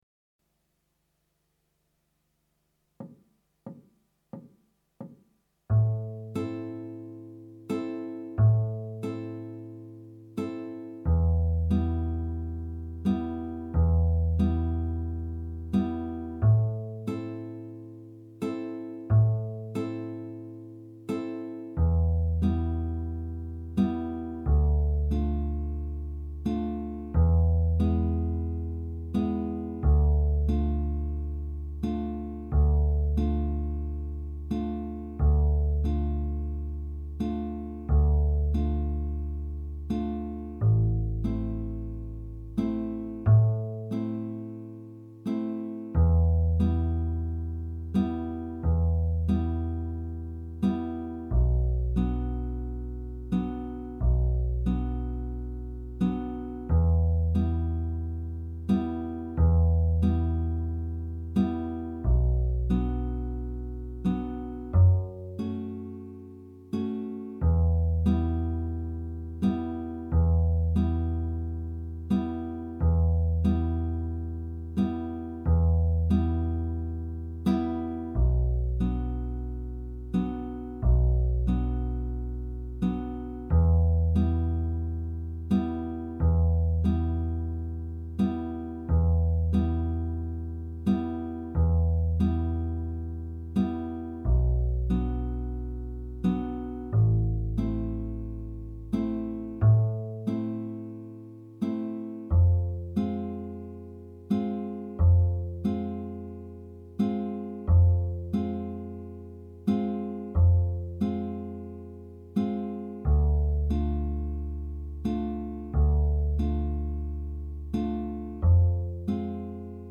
minus Guitar 3